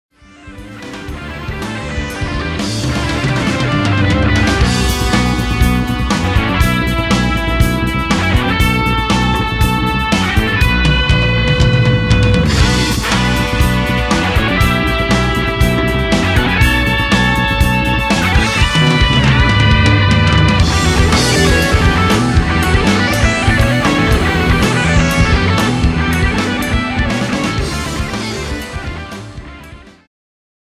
ジャンル Fusion
Jazz-Rock系
Progressive
インストゥルメンタル